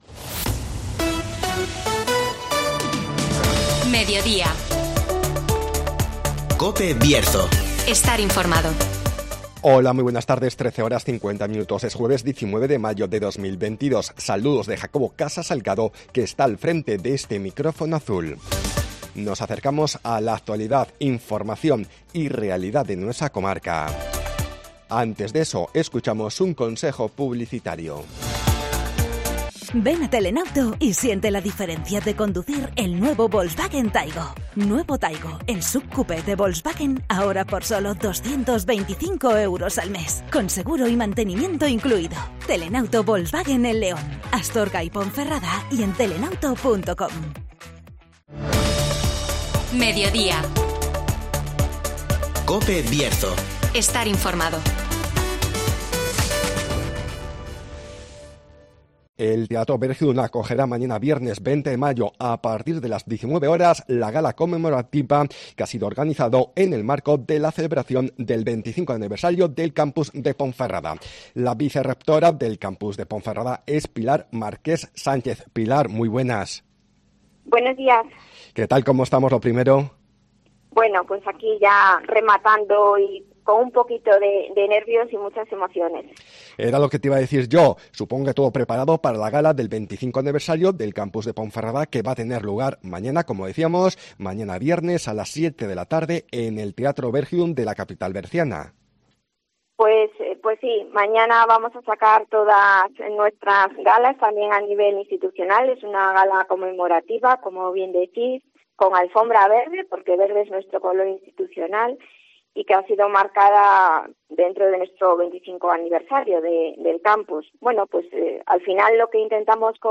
ACTUALIDAD